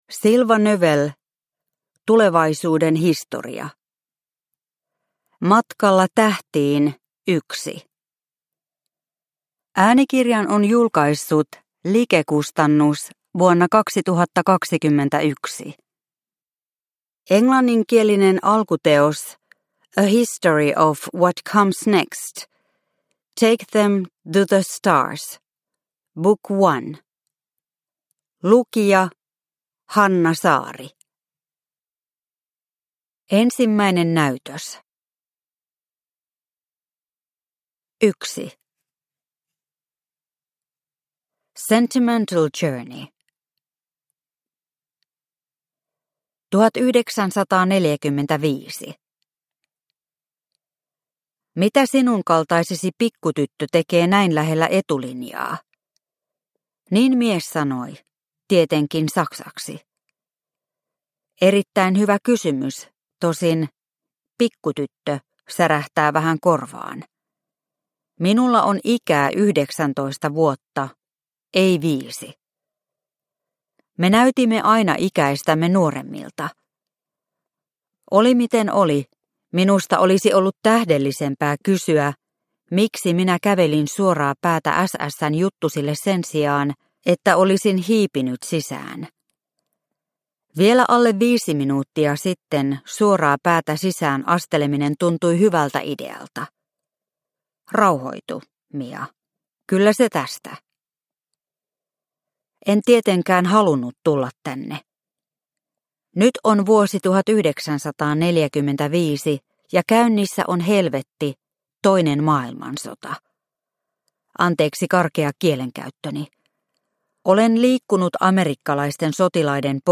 Tulevaisuuden historia – Ljudbok – Laddas ner